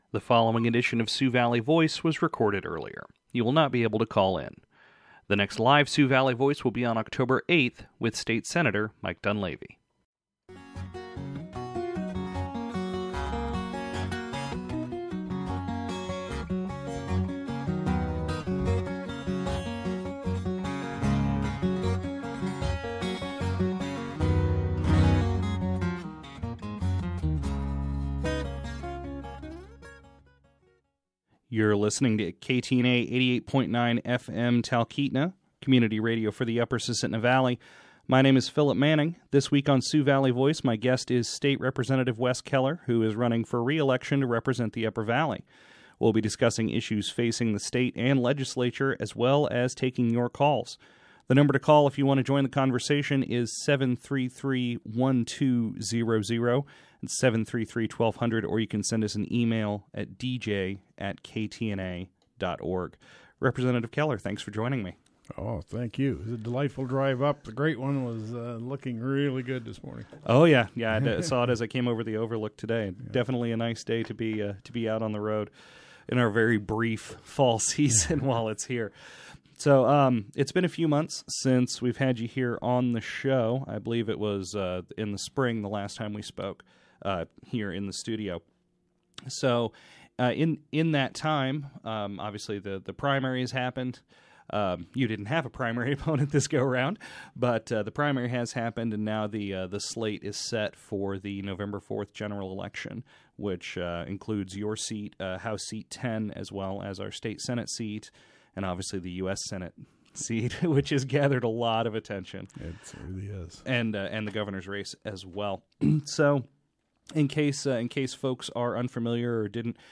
spoke with State Representative Wes Keller, who is running for re-election on November 4th.